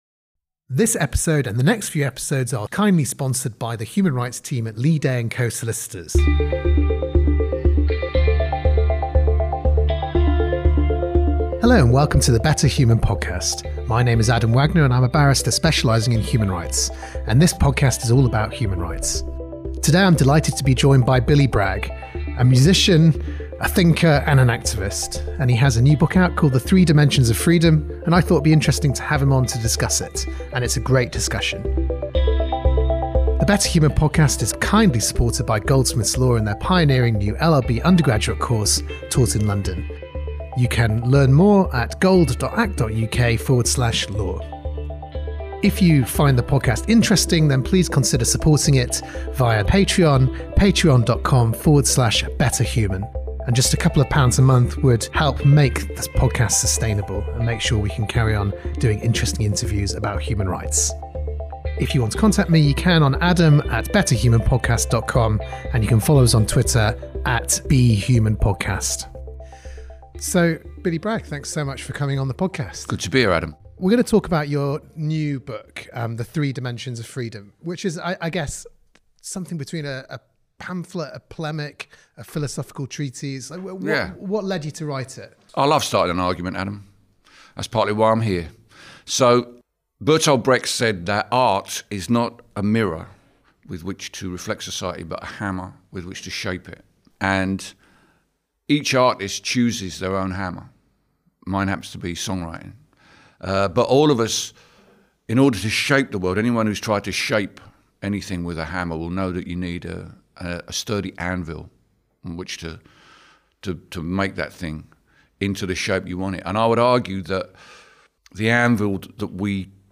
I am delighted to be joined in this episode by Billy Bragg, singer and activist. Billy and I talk about his new book, the Three Dimensions of Freedom, and much else beside.